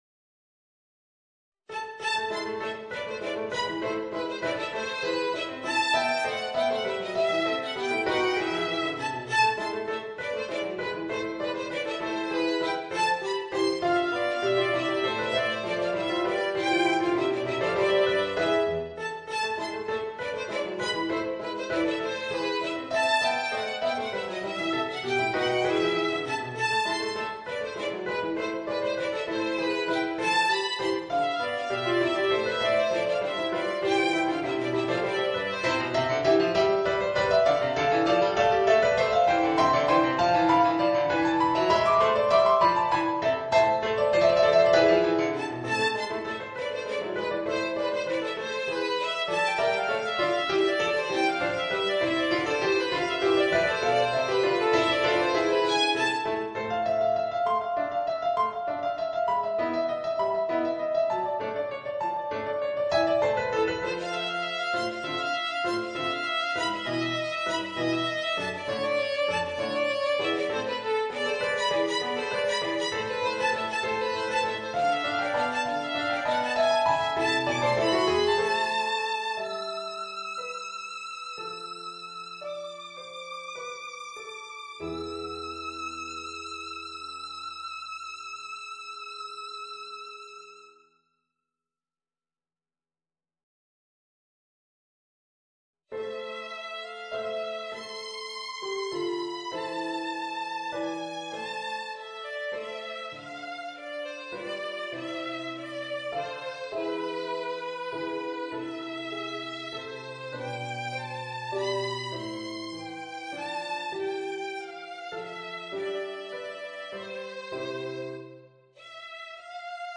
Voicing: Violin and Organ